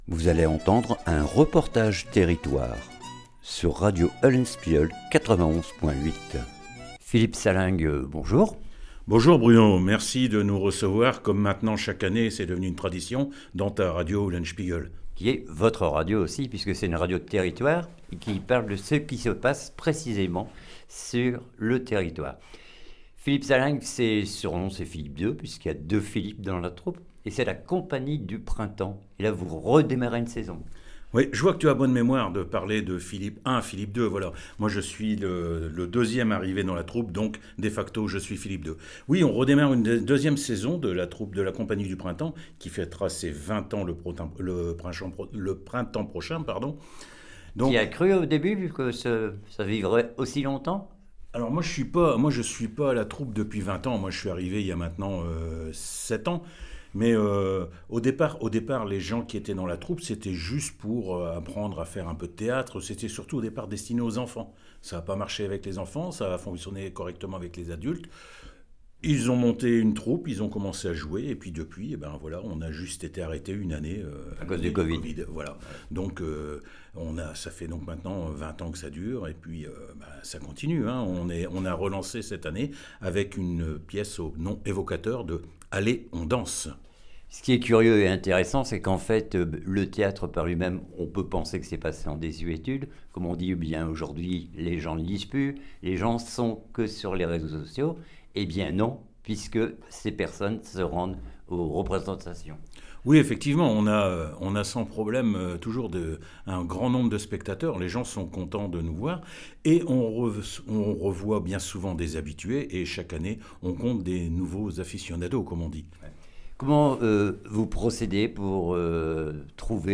REPORTAGE TERRITOIRE LA COMPAGNIE DU PRINTEMPS